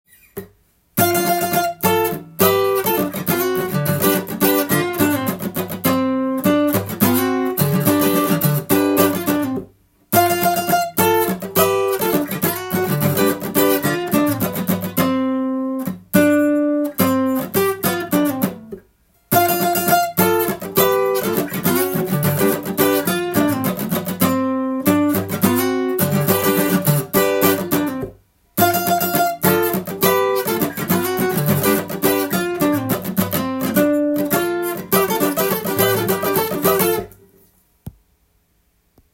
アコースティックギターでも弾いてみました